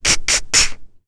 Requina-Vox_Attack2.wav